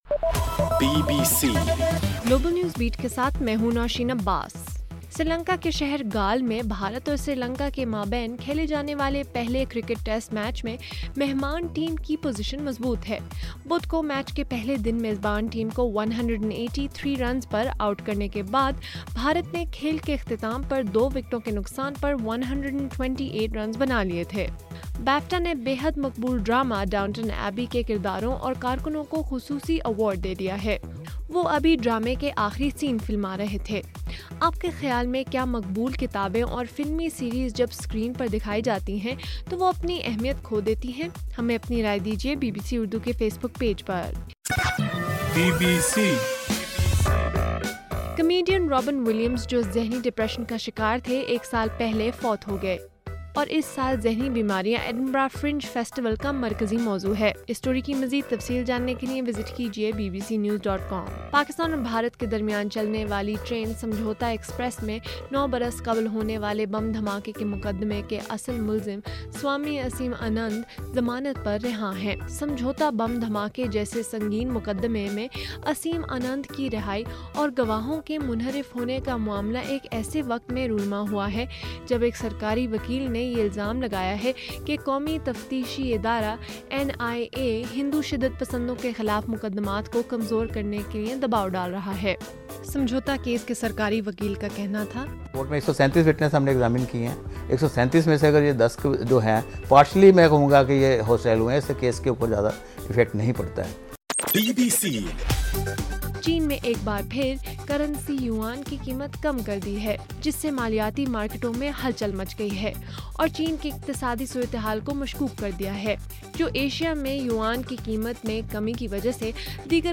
اگست 12: رات 9بجے کا گلوبل نیوز بیٹ بُلیٹن